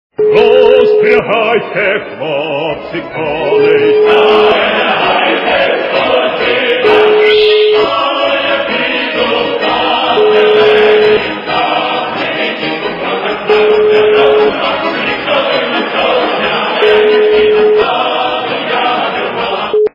- народные
При заказе вы получаете реалтон без искажений.